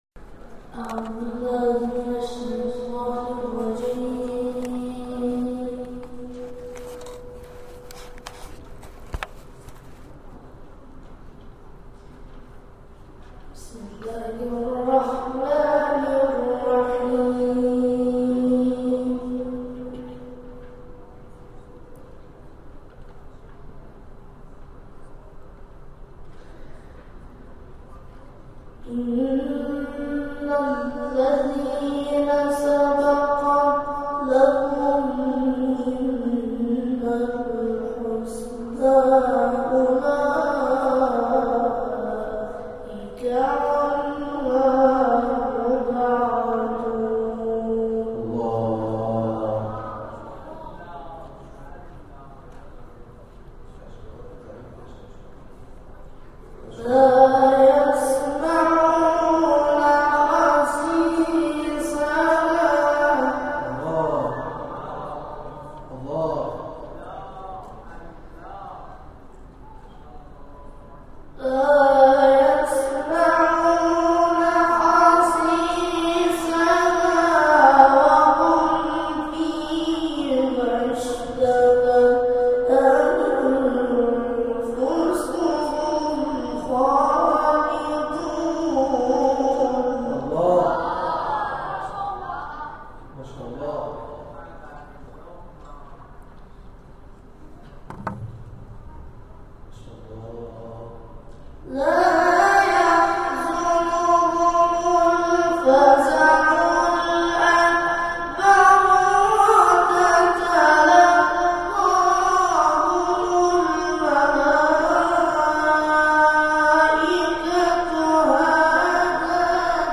در پایان تلاوت های منتخب این مراسم ارائه می‌شود.
تلاوت